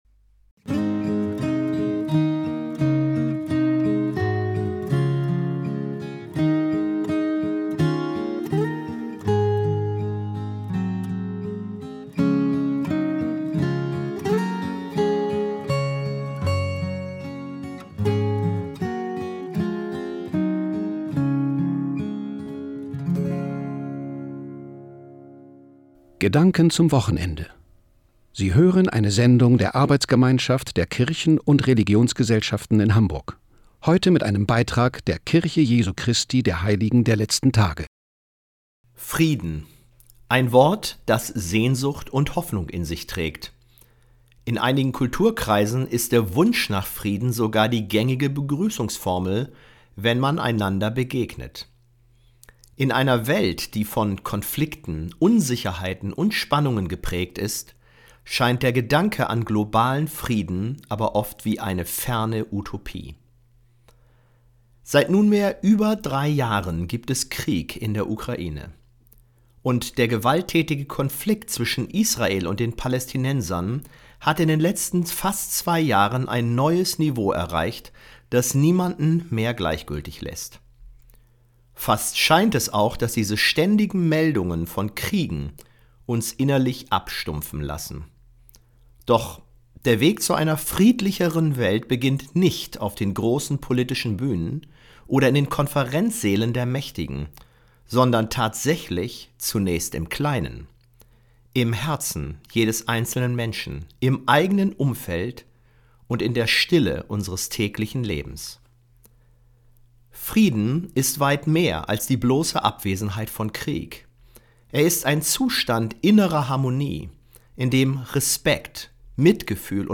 Radiobotschaften vom Mai, Juni und Juli